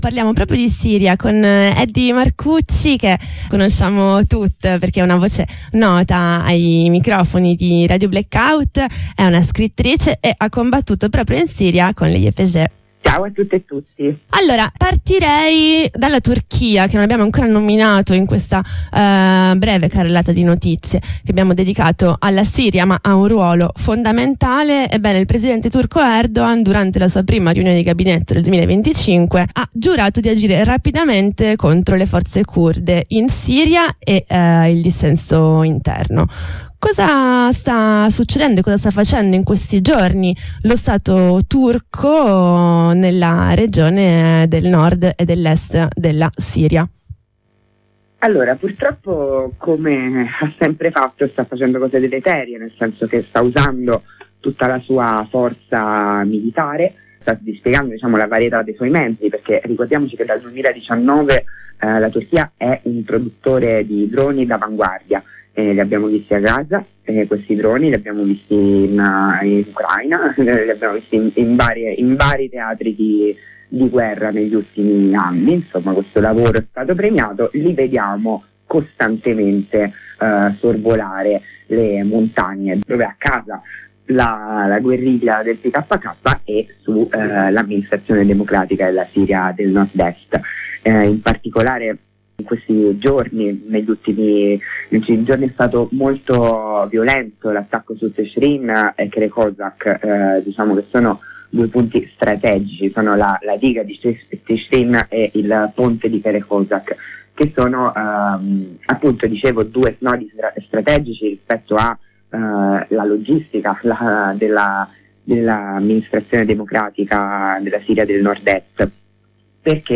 Abbiamo raggiunto telefonicamente